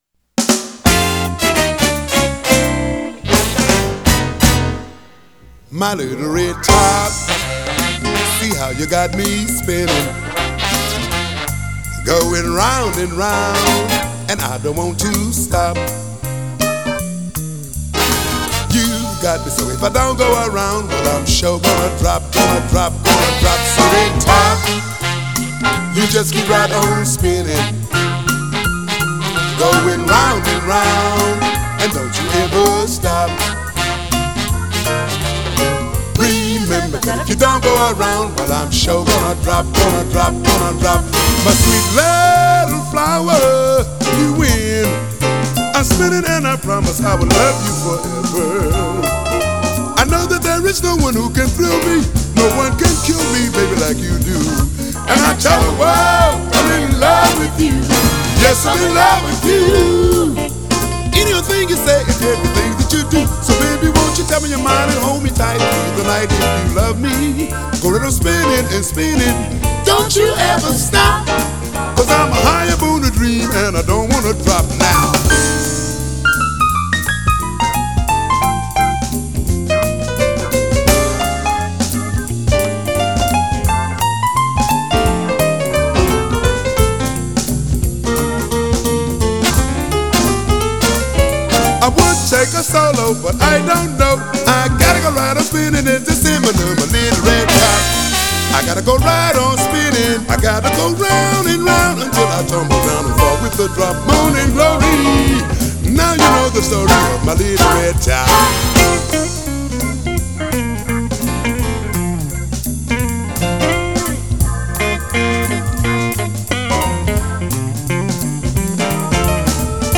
앨범은 재즈 , 블루스 및 소울 표준으로 구성되며 솔로와 듀엣이 혼합되어 있습니다.